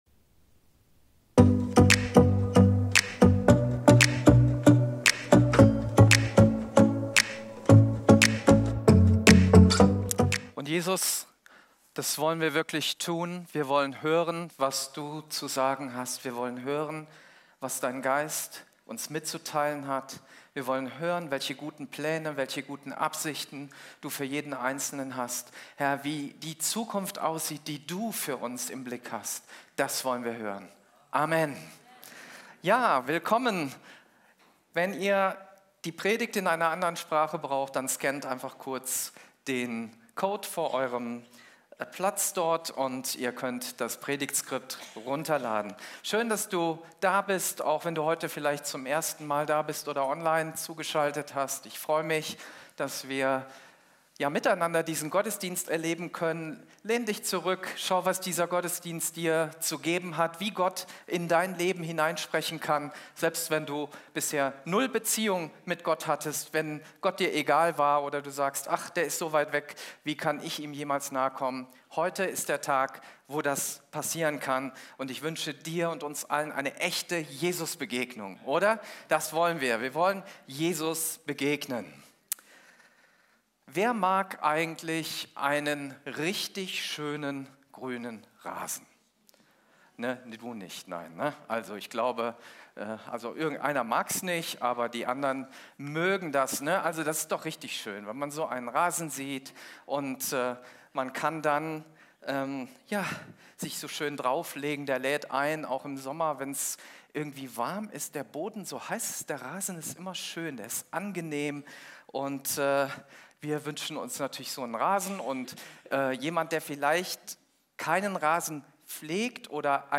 Live-Gottesdienst aus der Life Kirche Langenfeld.
Kategorie: Sonntaggottesdienst Predigtserie: Die Gleichnisse des Königs